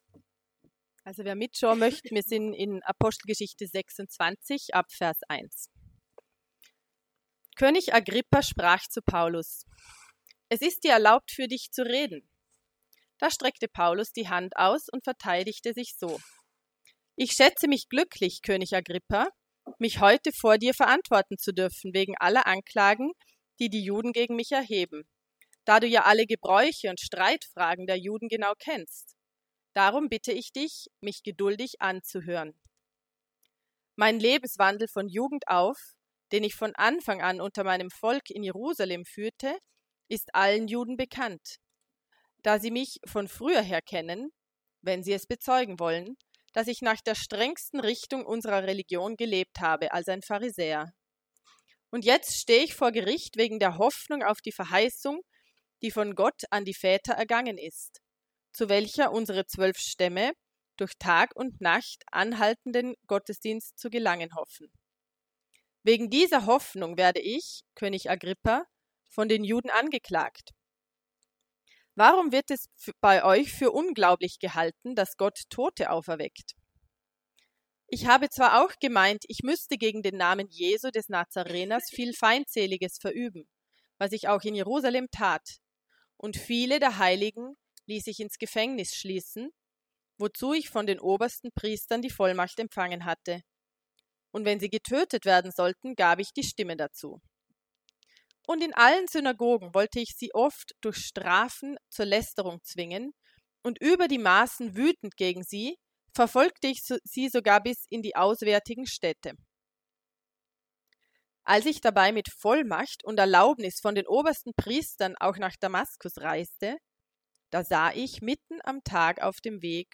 Das Apostolische Glaubensbekenntnis ist so eine Zusammenfassung „bodenständigen“ Glaubens. In zehn Predigten widmen wir uns diesen Wahrheiten mit Hilfe von Gottes Wort und bekommen festen Boden unter die Füße in Zeiten, wo so vieles sonst ins Wanken gerät.